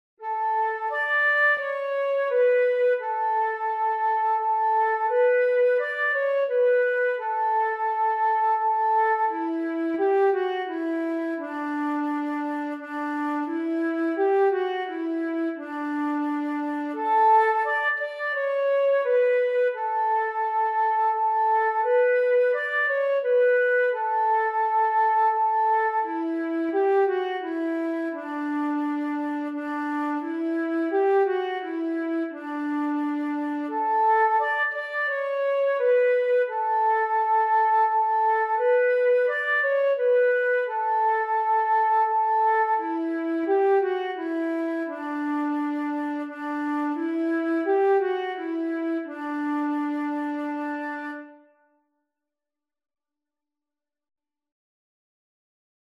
In höherer- und tiefer Tonart
Langsam, feierlich